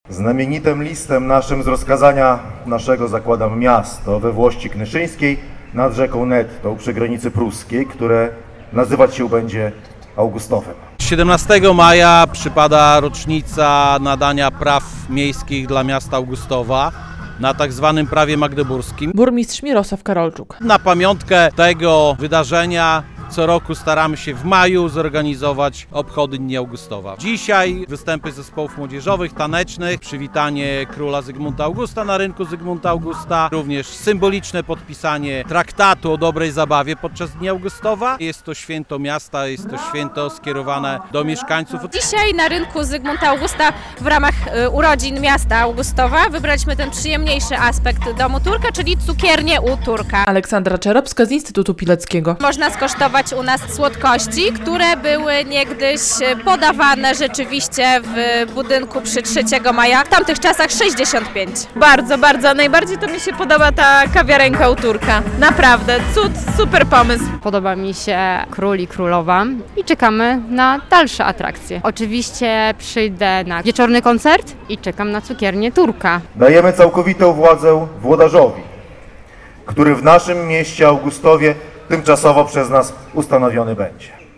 Jest to święto skierowane przede wszystkim do mieszkańców – mówił burmistrz Mirosław Karolczuk.